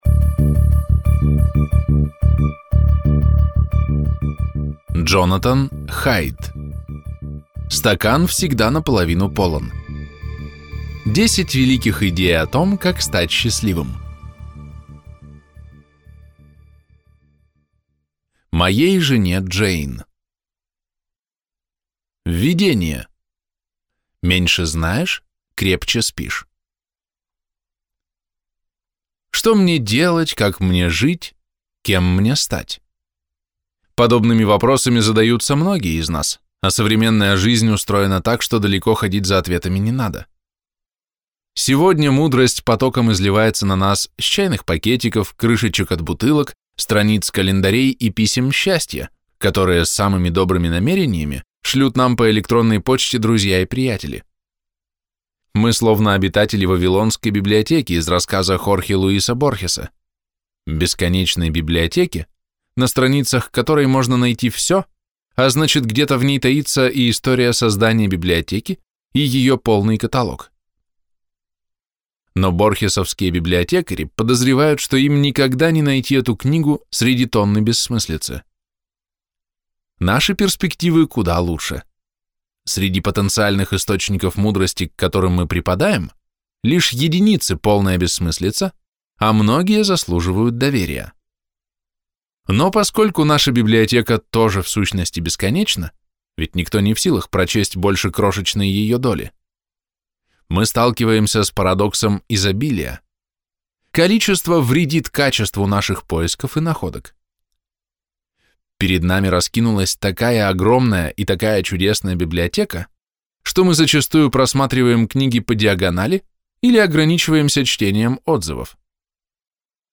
Аудиокнига Стакан всегда наполовину полон! 10 великих идей о том, как стать счастливым | Библиотека аудиокниг